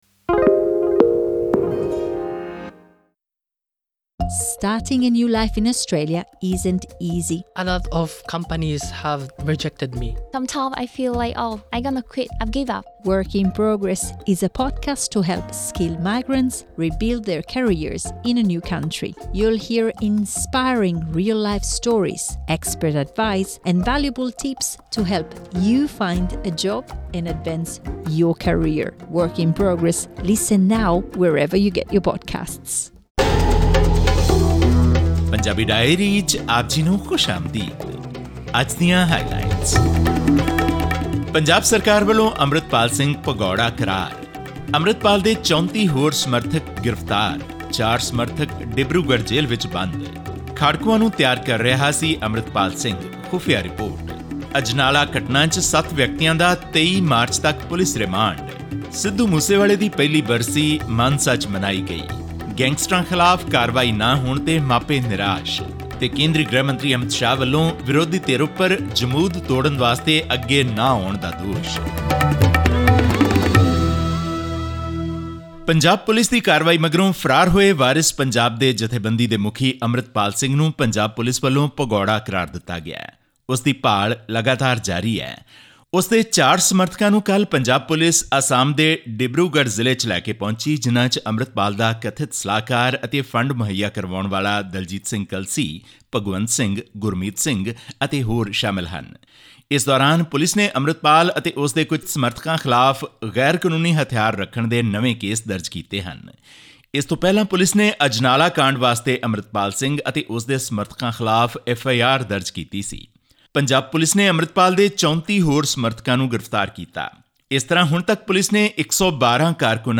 ਇਸ ਸਬੰਧੀ ਹੋਰ ਵੇਰਵੇ ਅਤੇ ਖ਼ਬਰਾਂ ਲਈ ਸੁਣੋ ਇਹ ਆਡੀਓ ਰਿਪੋਰਟ।